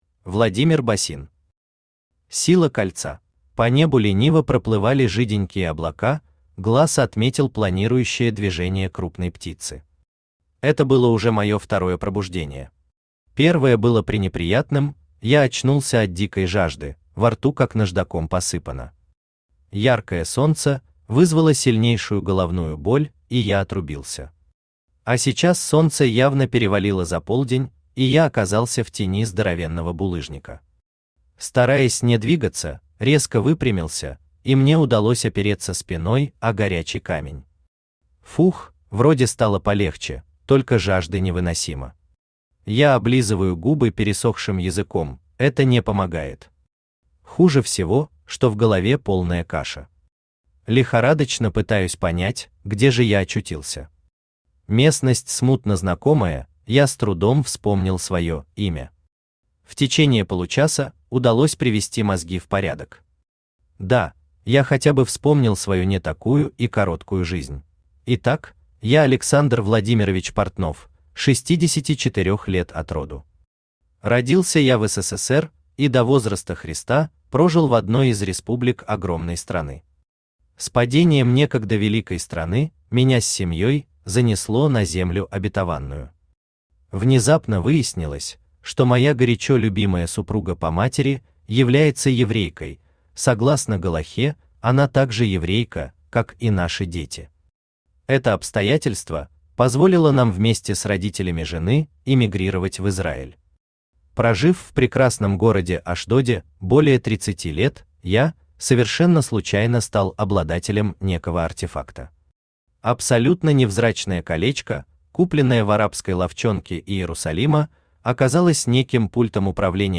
Аудиокнига Сила кольца | Библиотека аудиокниг
Aудиокнига Сила кольца Автор Владимир Георгиевич Босин Читает аудиокнигу Авточтец ЛитРес.